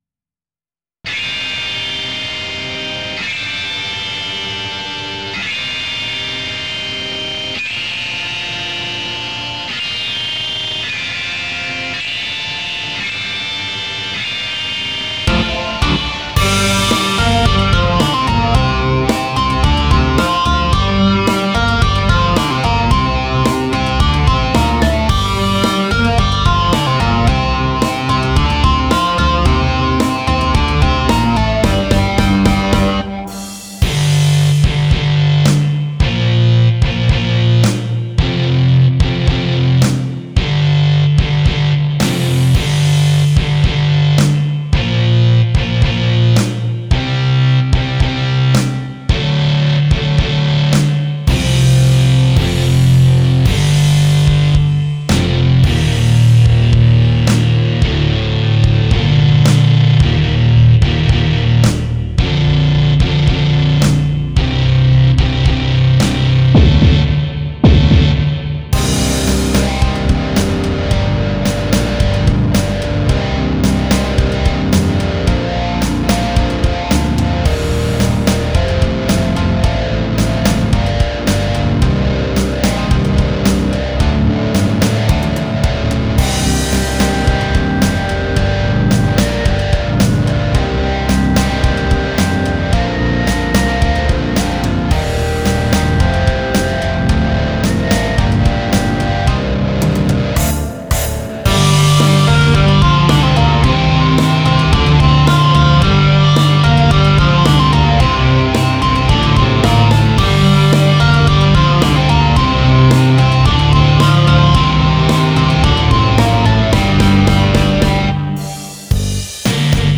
初めて、アナログ弾き語りを先に始めた曲です。